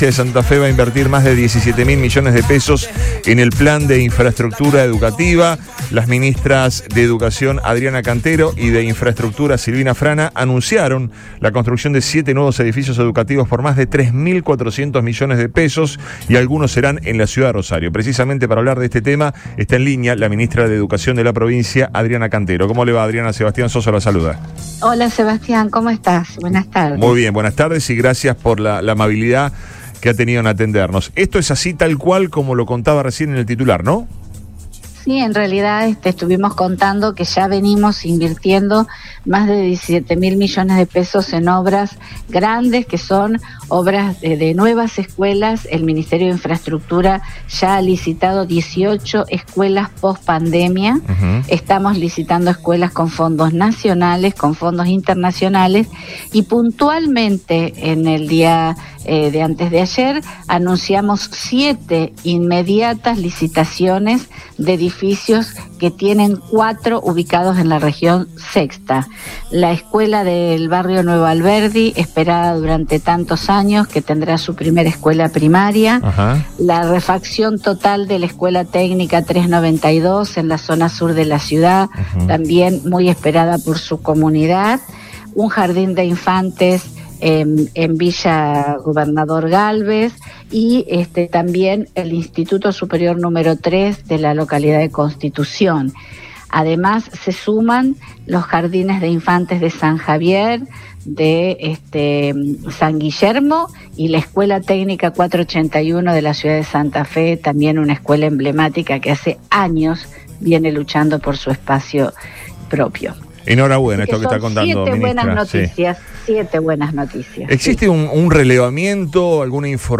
EN RADIO BOING
Cantero habló en Radio Boing y dio mas precisiones acerca de la medida.